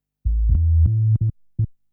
SNTHBASS028_PROGR_125_A_SC3.wav